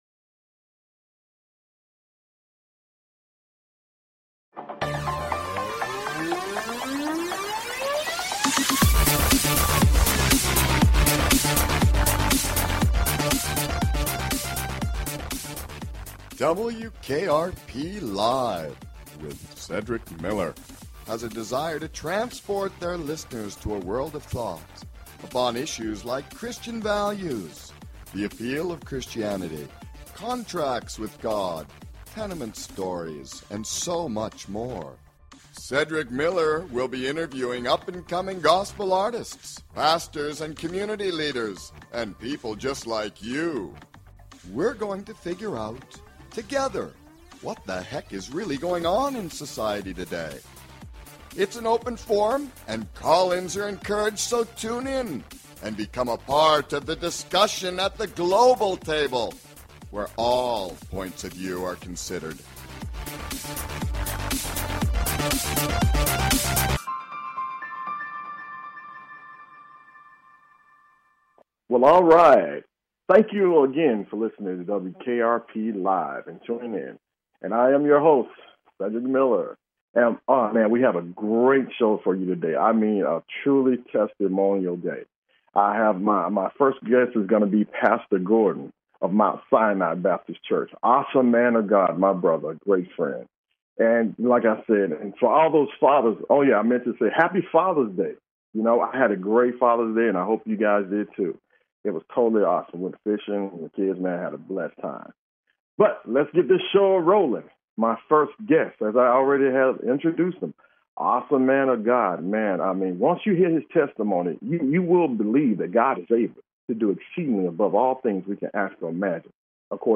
We will be interviewing up and coming Gospel artists, Pastors, Community Leaders and people just like you!